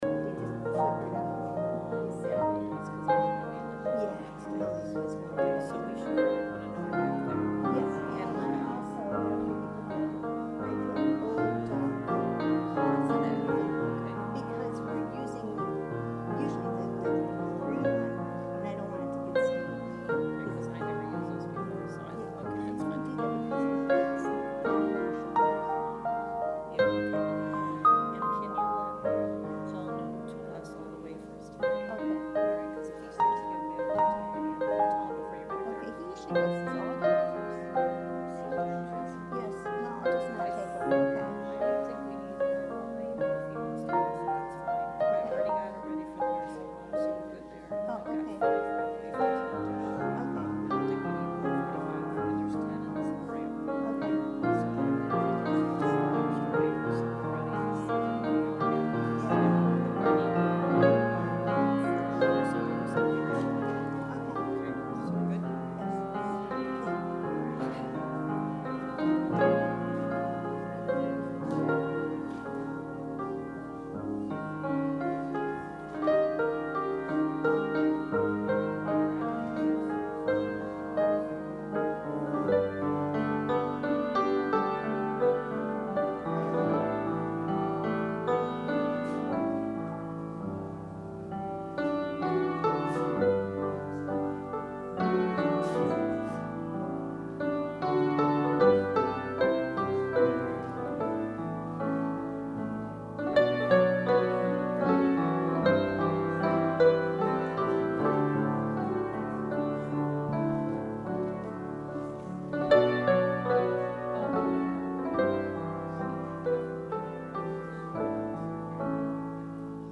Sermon – December 8, 2019
advent-sermon-december-8-2019.mp3